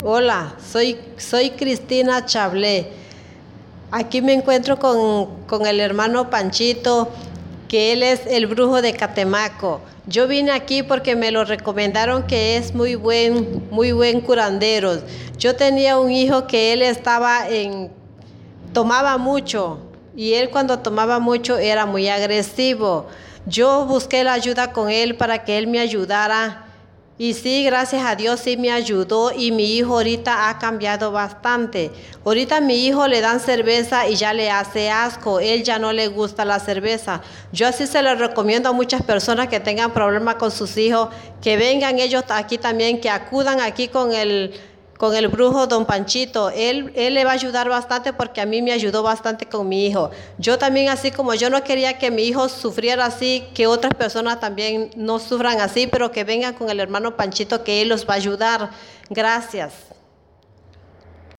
Testimonios reales